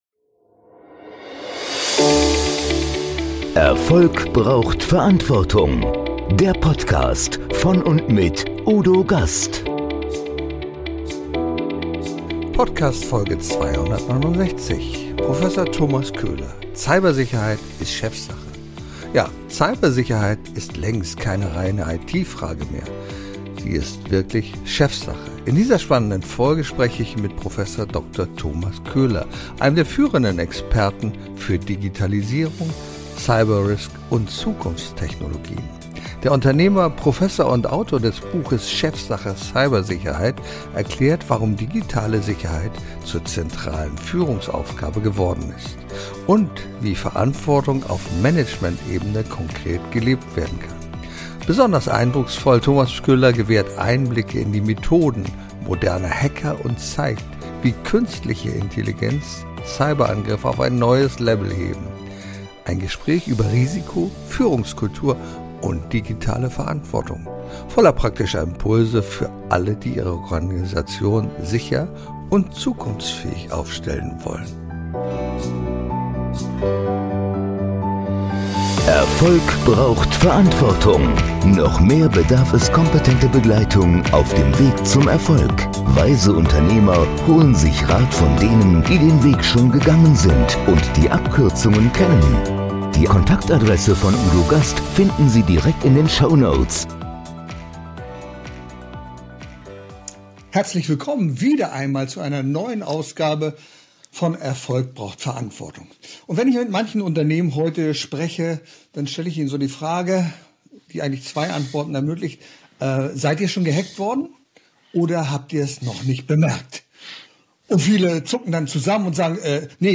Ein Gespräch über Risiko, Führungskultur und digitale Verantwortung – voller praktischer Impulse für alle, die ihre Organisation sicher und zukunftsfähig aufstellen wollen.